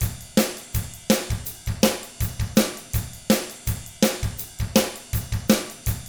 164ROCK T7-R.wav